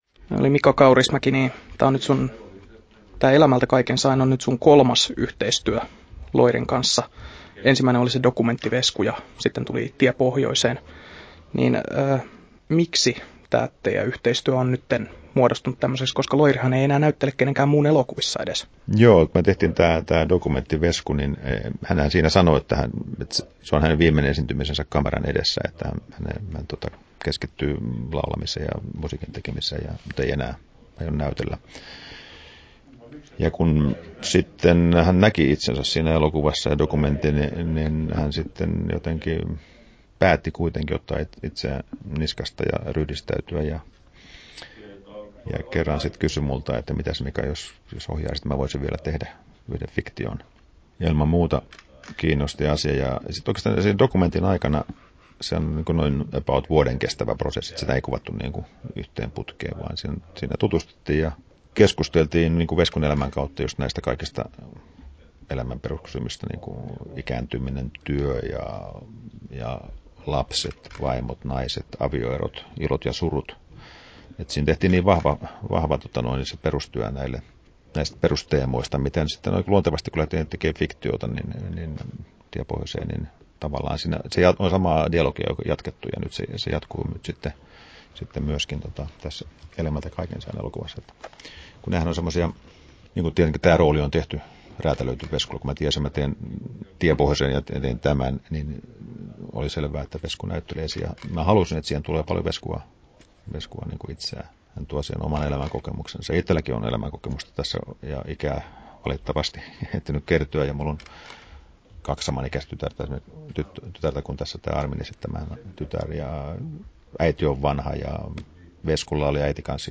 Haastattelussa Mika Kaurismäki Kesto: 12'52" Tallennettu: 19.8.2015, Turku Toimittaja